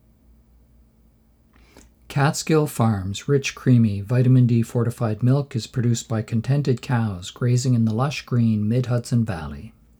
How about you post a ten second voice test with no corrections at all.
Many thanks - here you go, with raw voice from the NTG3 through a Scarlett Studio 2i2: